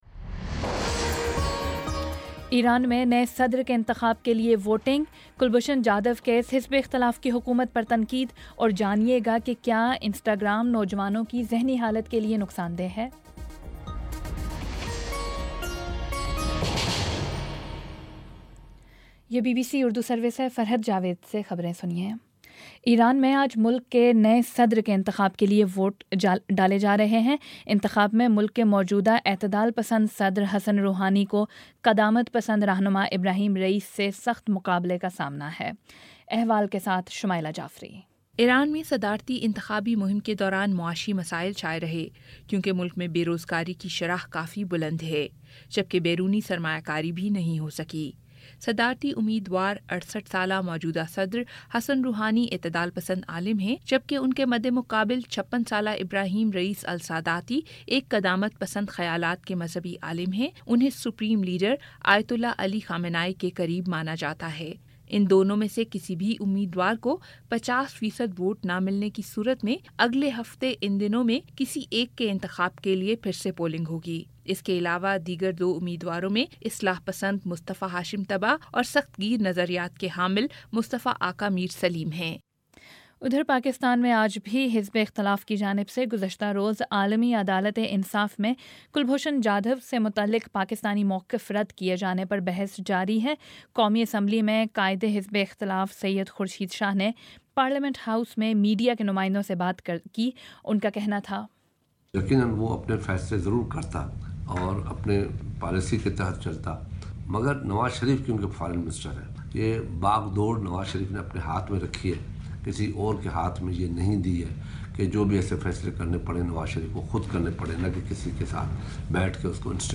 مئی 19 : شام چھ بجے کا نیوز بُلیٹن